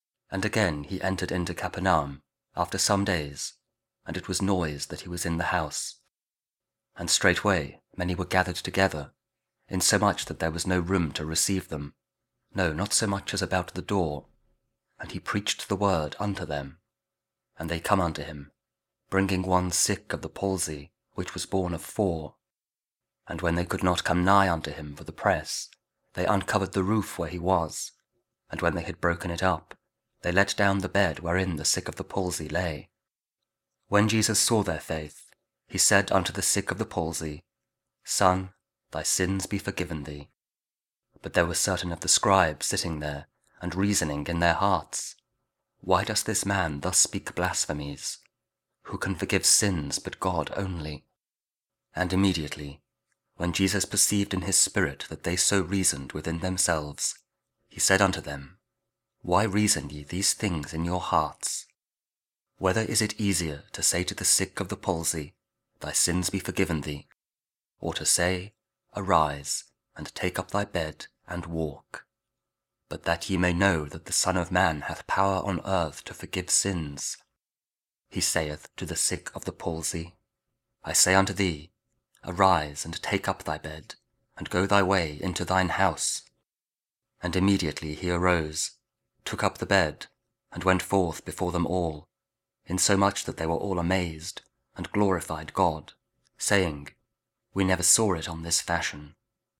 Mark 2: 1-12 (cf. Luke 5: 18-26 & Matthew 9: 2-8) 7th Sunday Year B and Week 1 Ordinary Time, Friday (Audio Bible KJV, Spoken Word)